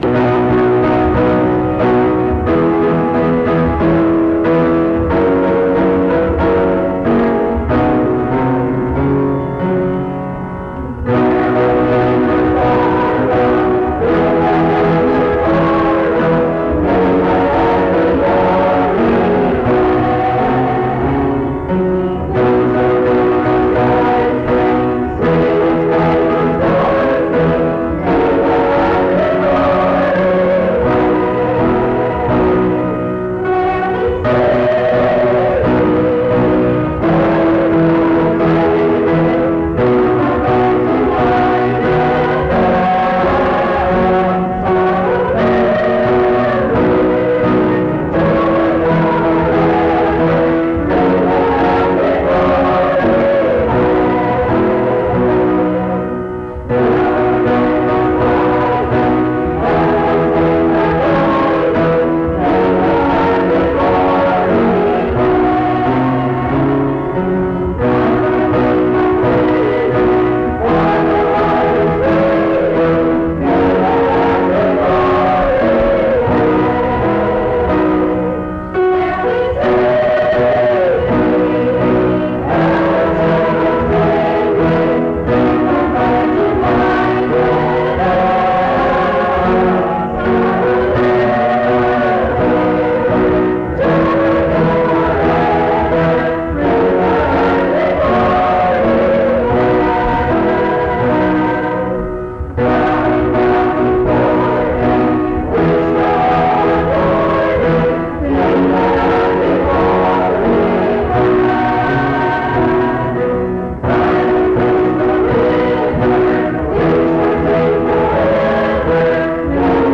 Mount Union Methodist Church II, rural, Monongalia County, WV, track 145H.